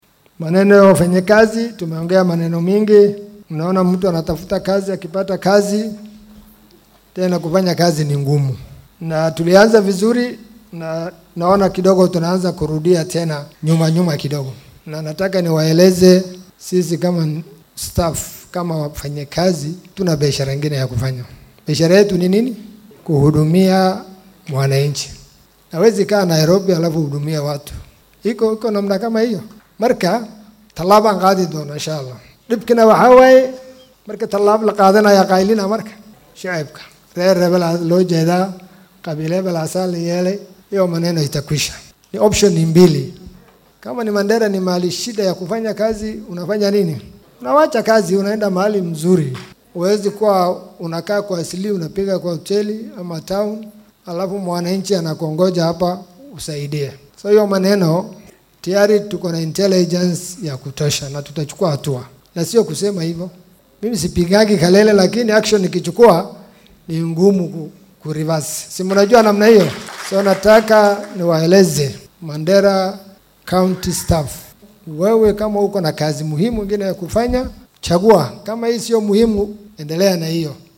Barasaabka Mandera ayaa hadalkan jeediyay xilli uu daah furayay deeqda ardayda looga bixiyo qarashaadka waxbarashada ee bursary-ga.